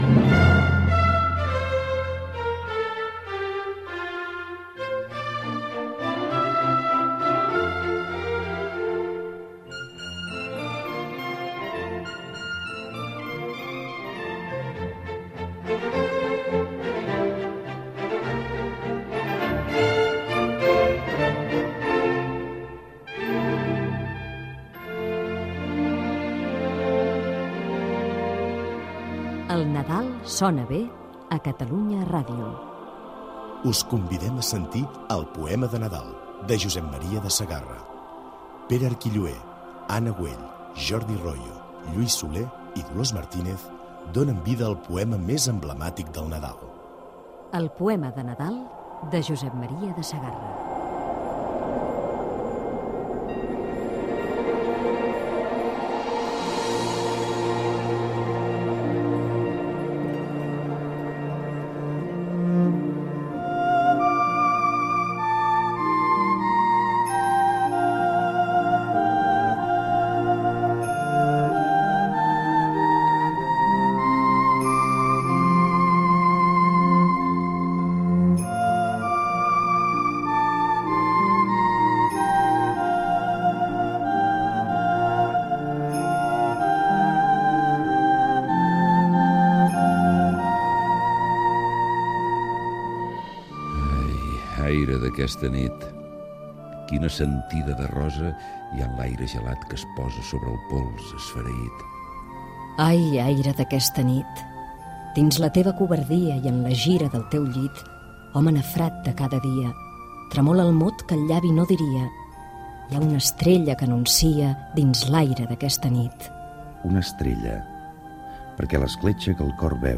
Careta del programa, amb el repartiment, i versió radiofònica
Gènere radiofònic Ficció